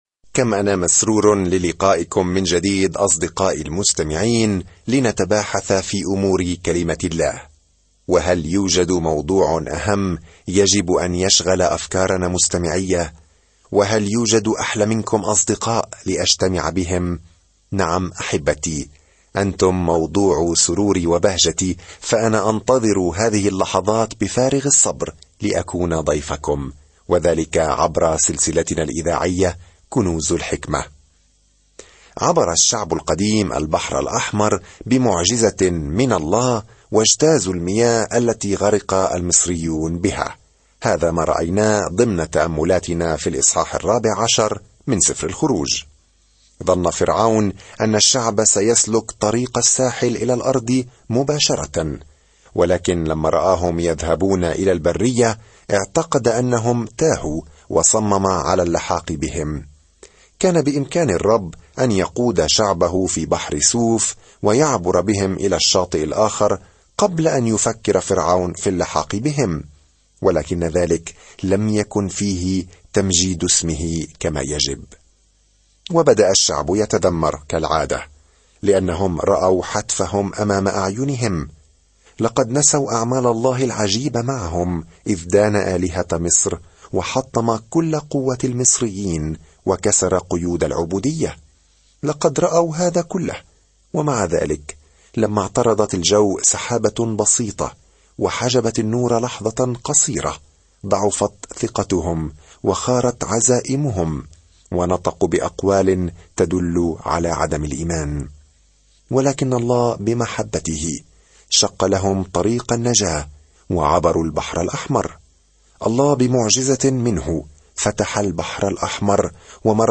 الكلمة اَلْخُرُوجُ 15 يوم 13 ابدأ هذه الخطة يوم 15 عن هذه الخطة يتتبع سفر الخروج هروب إسرائيل من العبودية في مصر ويصف كل ما حدث على طول الطريق. سافر يوميًا خلال سفر الخروج وأنت تستمع إلى الدراسة الصوتية وتقرأ آيات مختارة من كلمة الله.